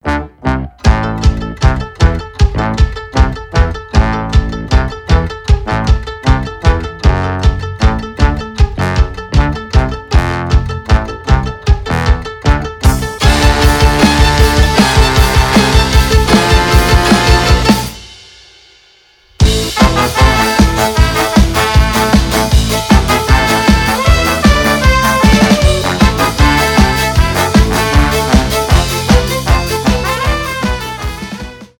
веселые
труба , рок
фолк , инструментальные , без слов